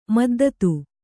♪ maddatu